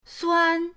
suan1.mp3